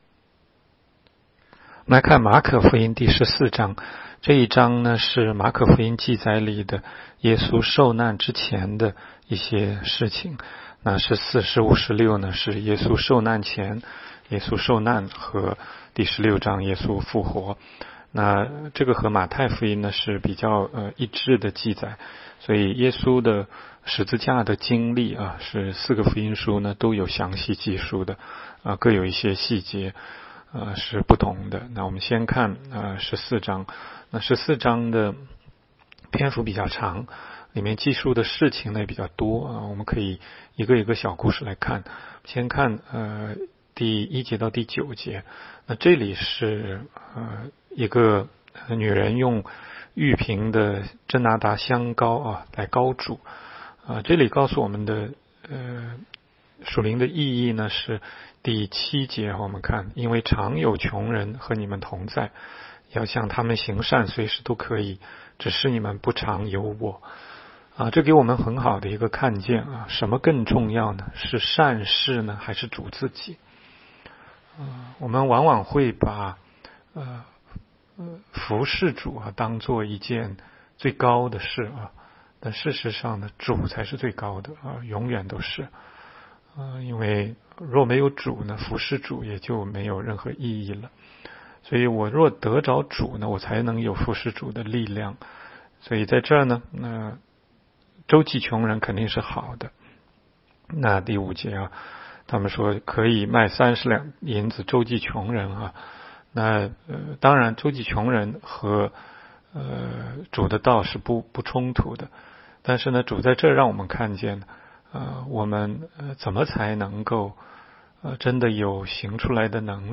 16街讲道录音 - 每日读经-《马可福音》14章